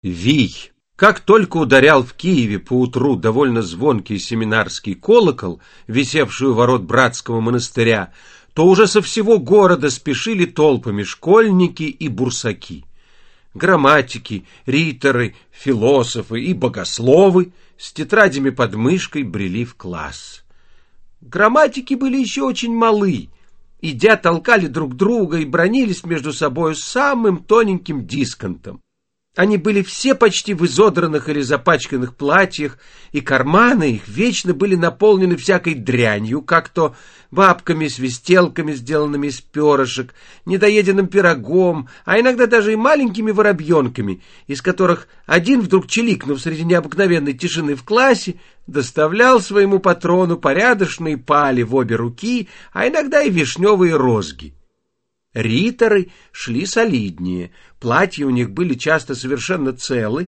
Аудиокнига Вий в исполнении Вениамина Смехова | Библиотека аудиокниг
Aудиокнига Вий в исполнении Вениамина Смехова Автор Николай Гоголь Читает аудиокнигу Вениамин Смехов.